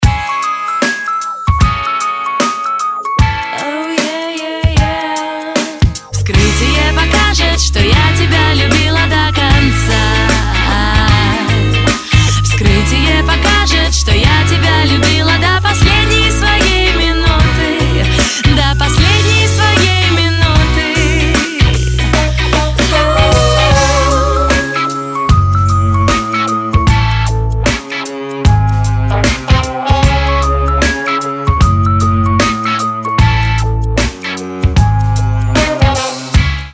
• Качество: 192, Stereo
романтичной песне